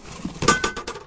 mail-slot.ogg